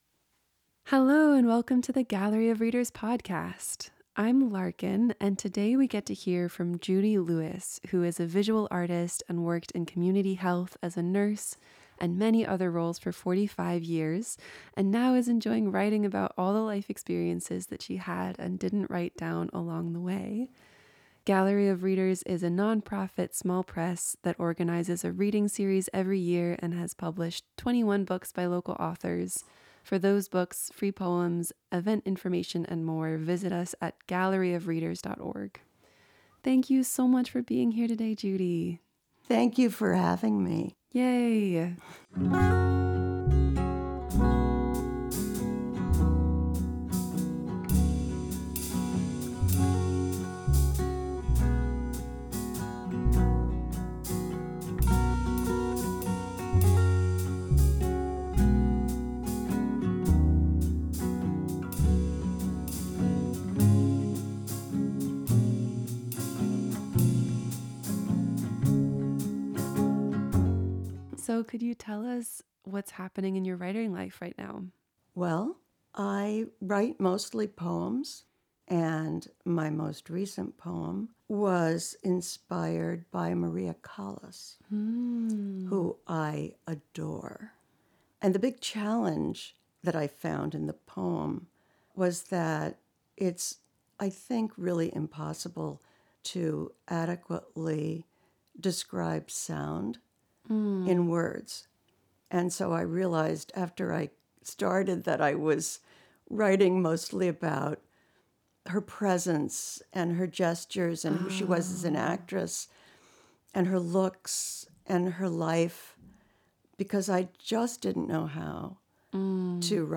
The reading portion of this episode was recorded live in front of a gallery of listeners in Florence, Massachusetts.